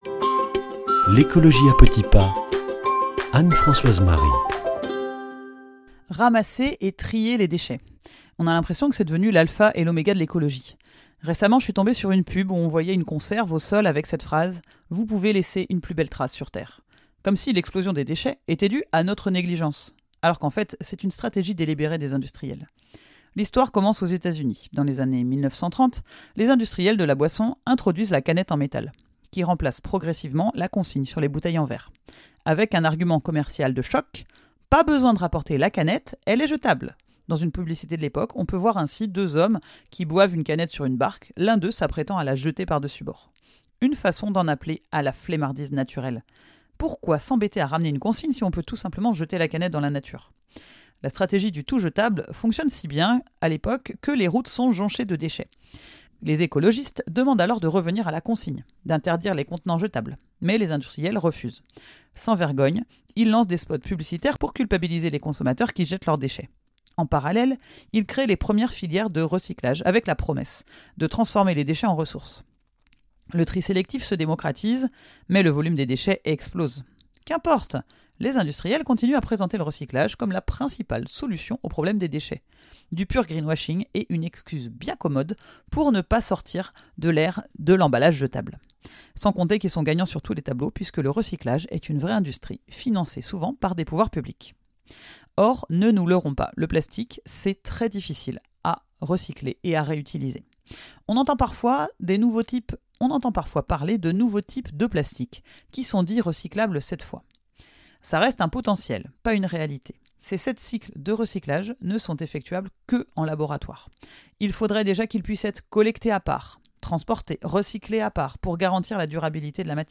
Présentatrice